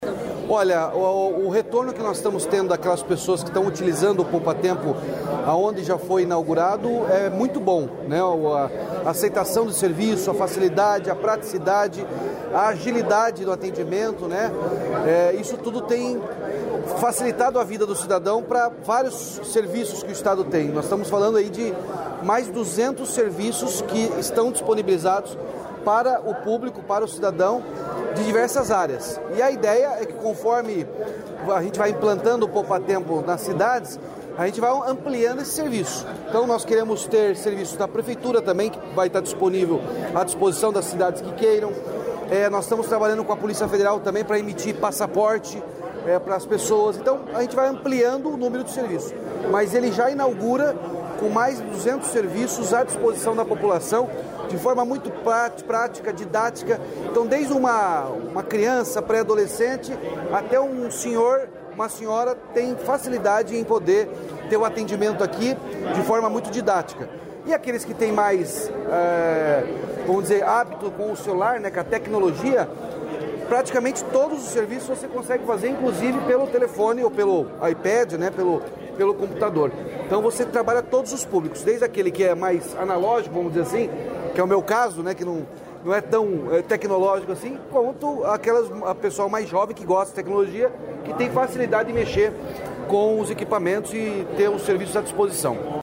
Sonora do governador Ratinho Junior sobre o Poupatempo em Maringá